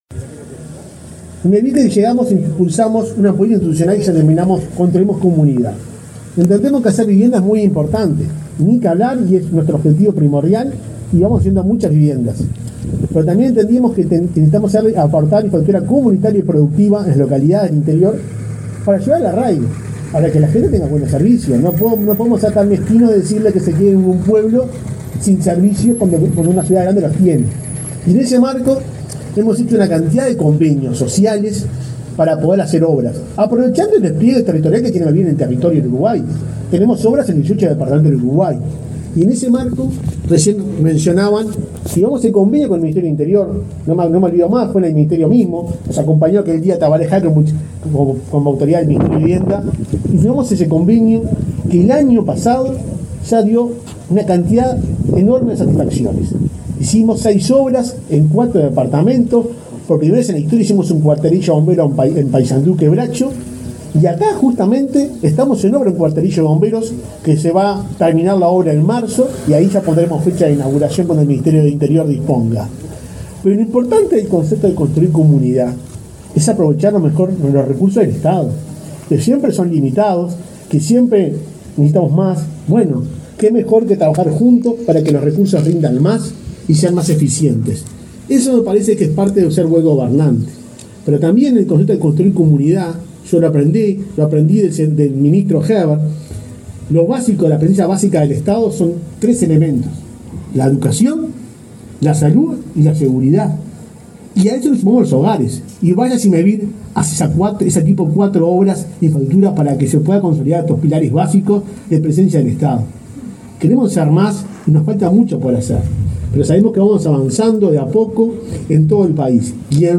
Palabra de autoridades en inauguración de subcomisaría en Rocha
Palabra de autoridades en inauguración de subcomisaría en Rocha 12/01/2023 Compartir Facebook X Copiar enlace WhatsApp LinkedIn El presidente de Mevir, Juan Pablo Delgado; el intendente Alejo Umpiérrez; la ministra de Vivienda, Irene Moreira; y el ministro del Interior, Luis Alberto Heber, participaron este jueves 12 en Rocha, de la inauguración de la subcomisaría de Punta del Diablo.